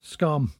[scAHM]